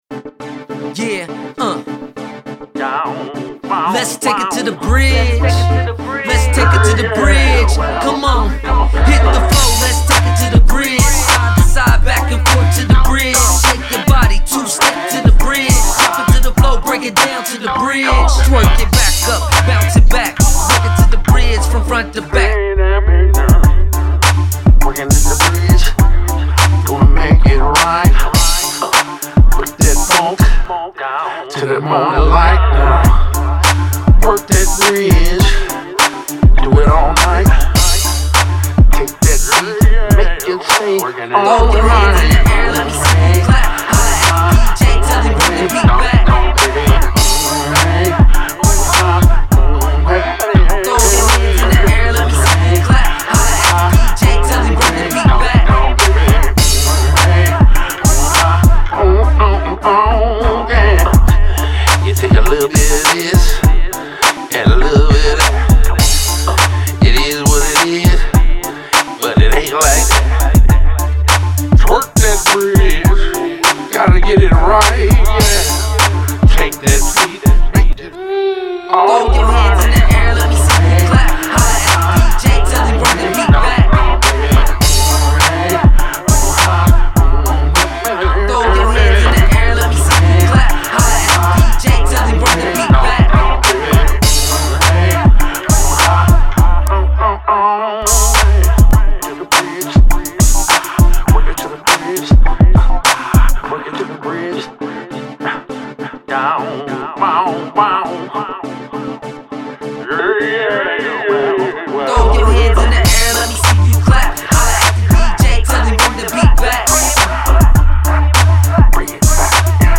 Old School Hip-Hop